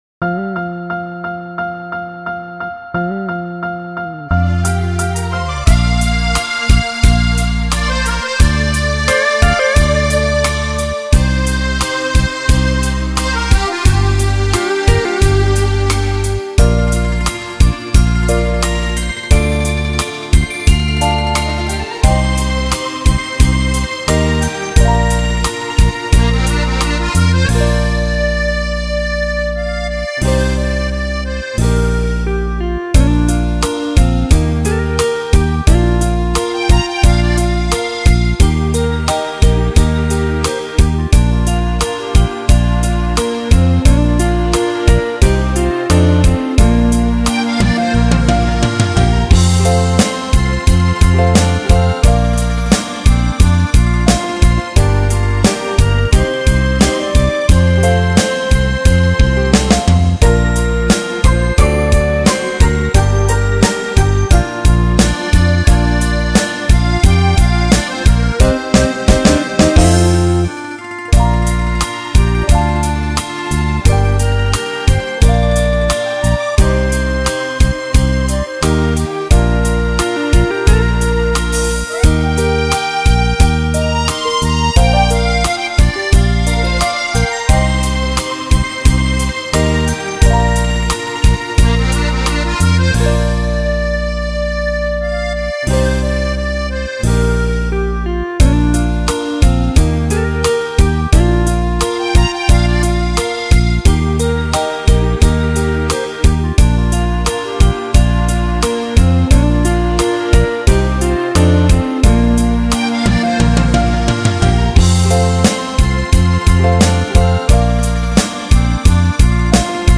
경음악